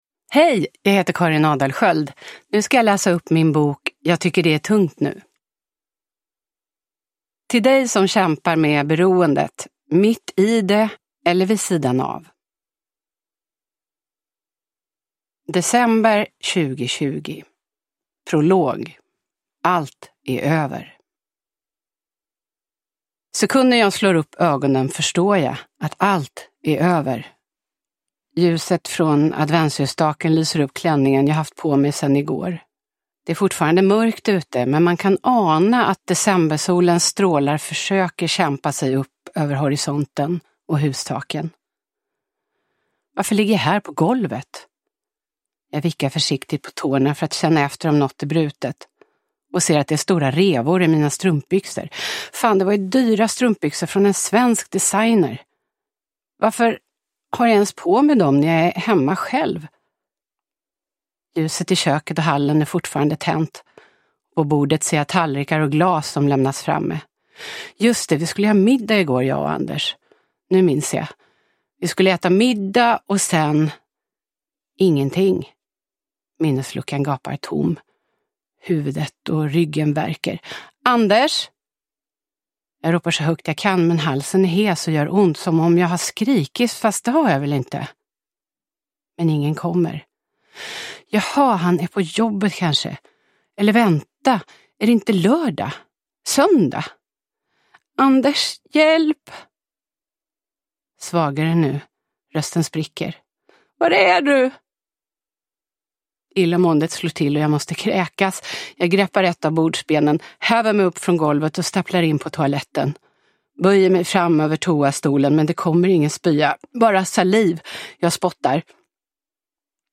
Jag tycker det är tungt nu – Ljudbok
Uppläsare: Karin Adelsköld